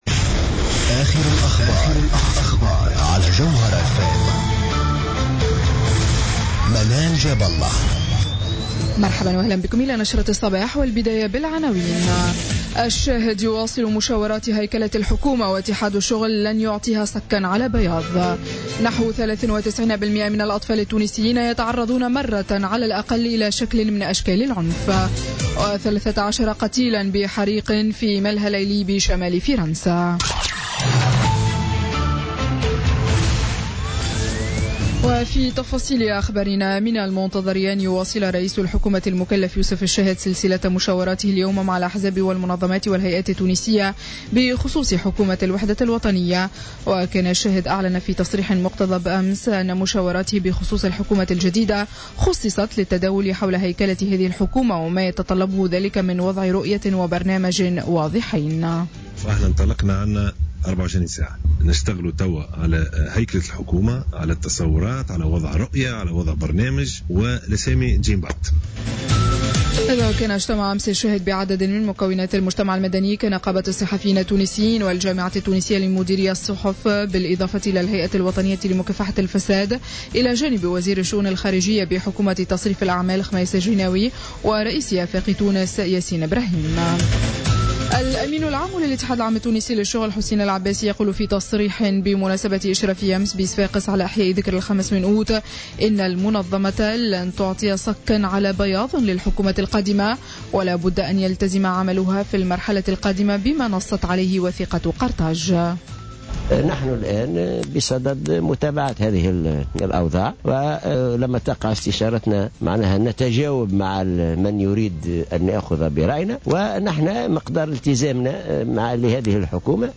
نشرة أخبار السابعة صباحا ليوم السبت 6 أوت 2016